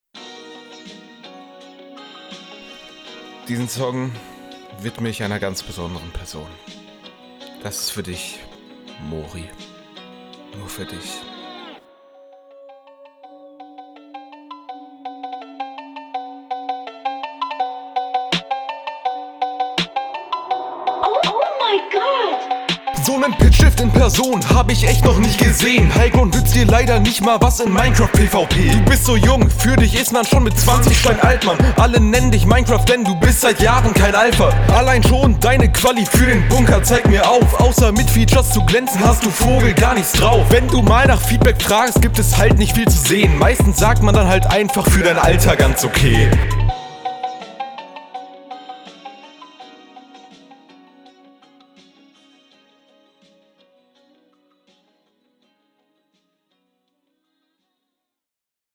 Flow: auch gut, PHONK Beats sind Liebe Text: gut Soundqualität: gut Allgemeines: Allgemeines goijigheriuogieorigouerpigoerhgiuer